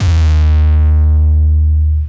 Long Dist 808 (D#).wav